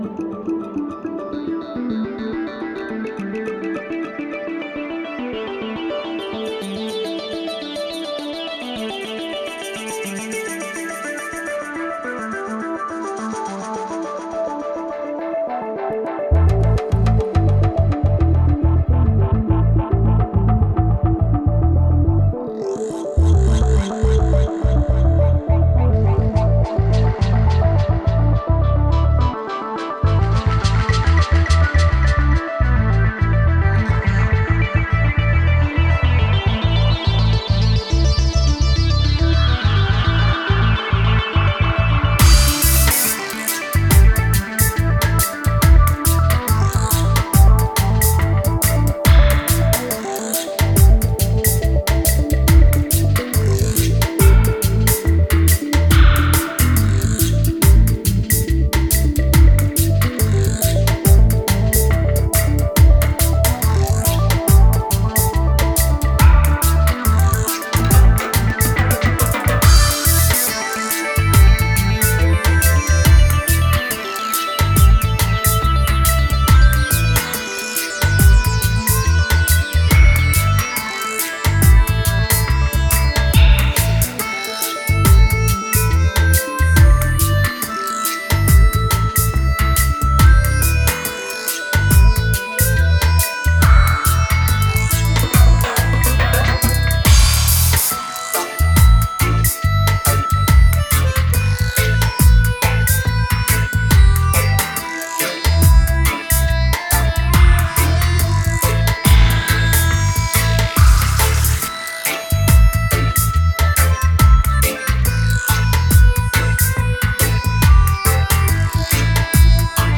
Genre: Dub, Psy-Dub.